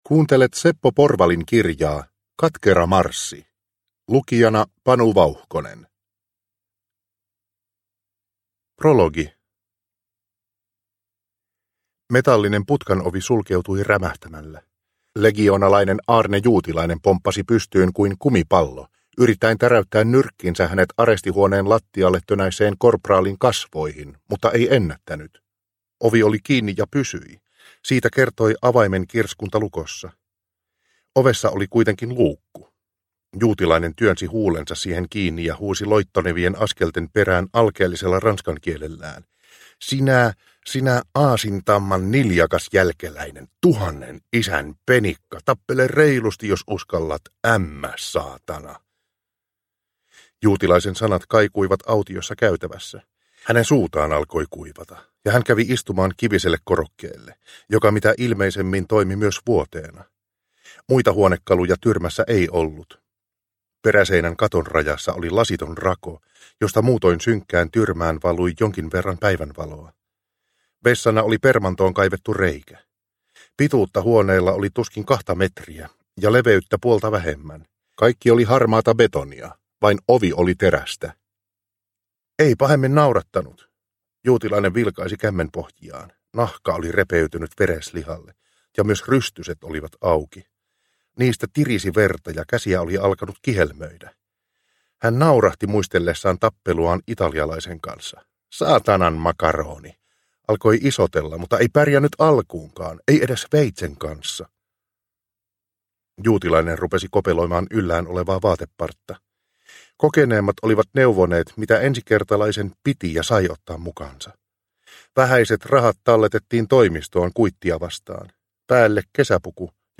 Katkera marssi – Ljudbok – Laddas ner